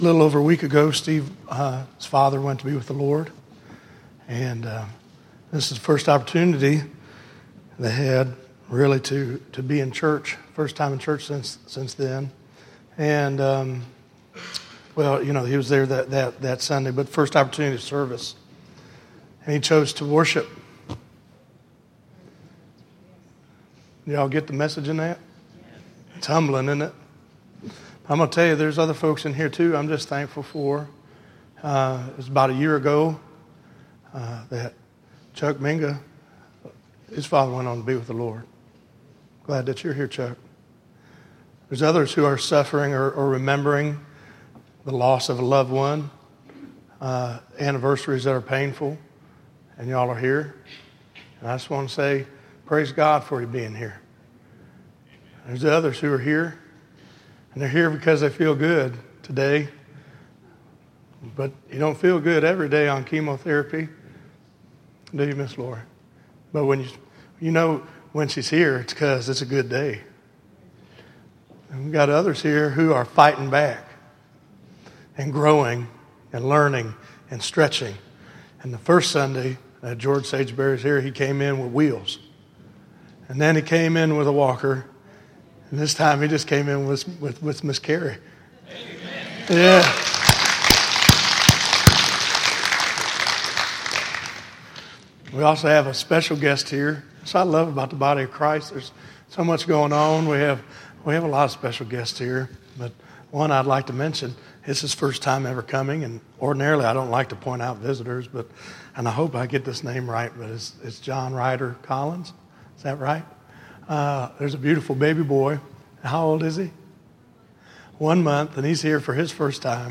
Listen to A People Ready for Revival - 03_30_2014_Sermon.mp3